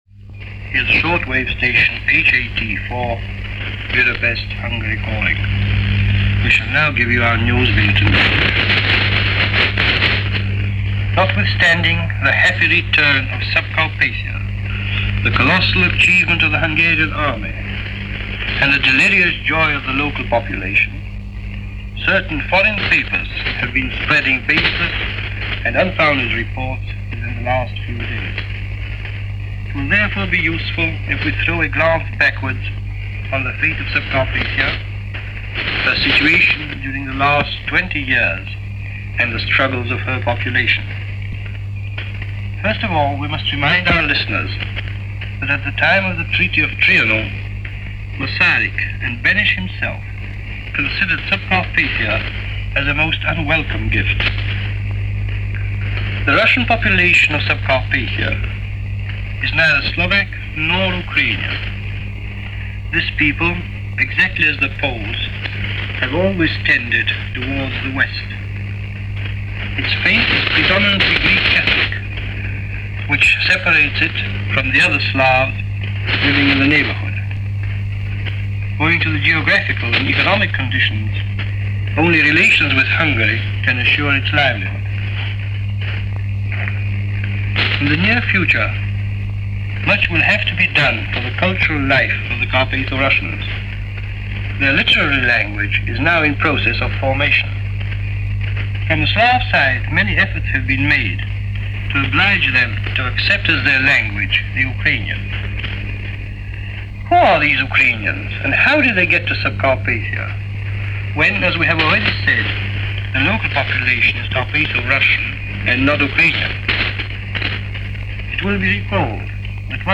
On March 21st 1939, Radio Hungary Radio broadcast a news report via its English Service, describing the takeover and justification for absorbing Carpatho-Ukraine into the Hungarian fold. Here is that newscast from Radio Hungary – it’s difficult to hear in places, as it’s a shortwave broadcast and the signal goes in and out.